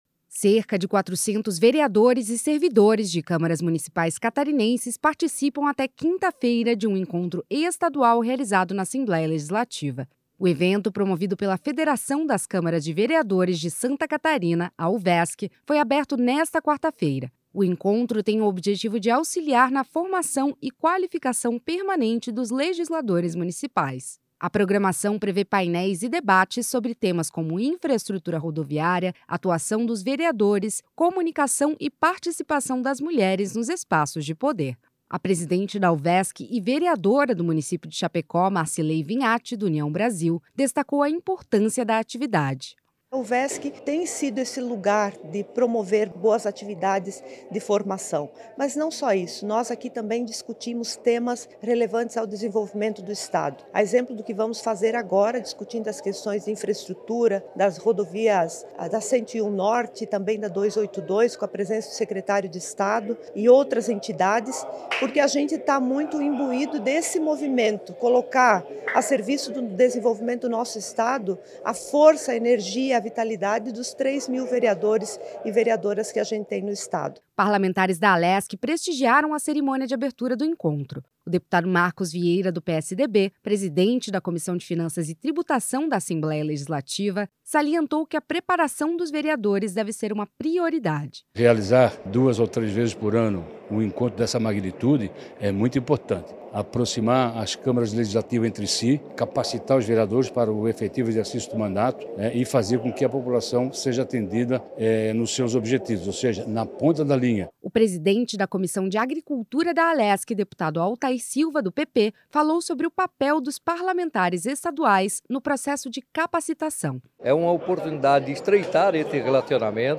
Entrevistas com:
- Marcilei Vignatti (União), vereadora de Chapecó e presidente da Uvesc;
- deputado Marcos Vieira, presidente da Comissão de Finanças e Tributação da Alesc;